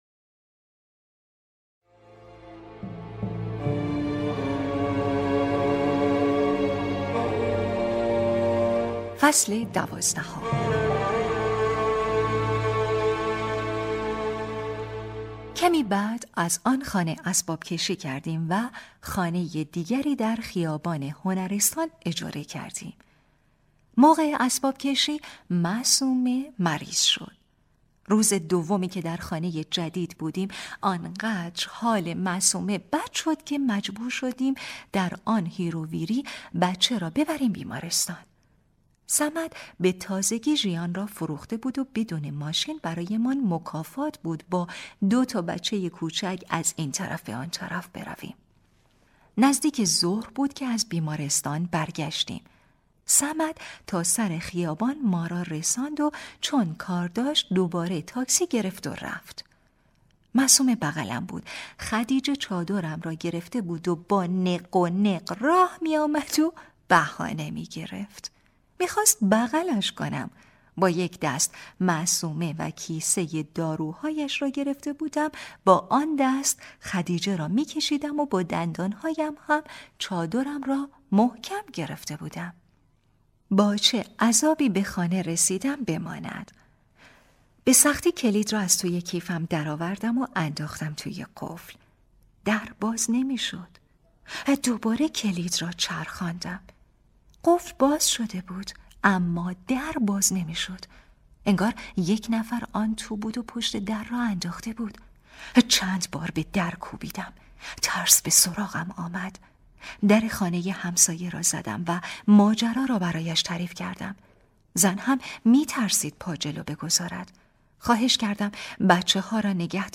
کتاب صوتی | دختر شینا (10)
# روایتگری